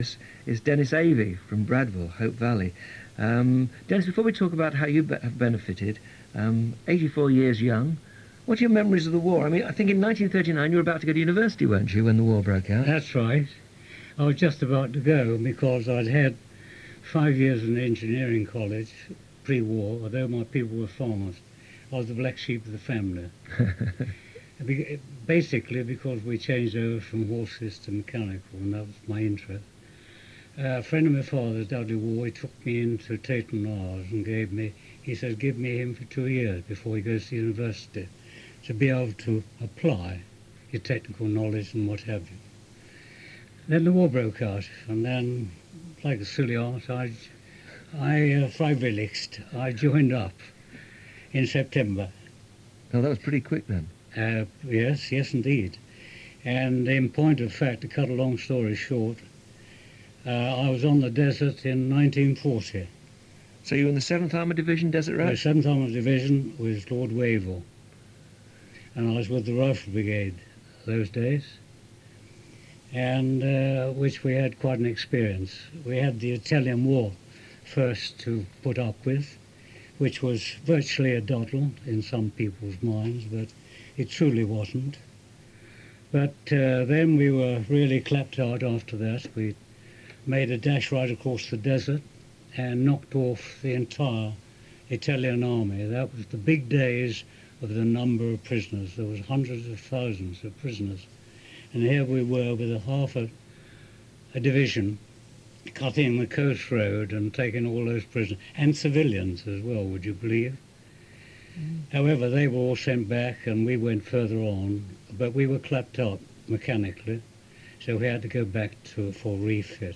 Avey, Denis (audio) BBC Radio Derby interview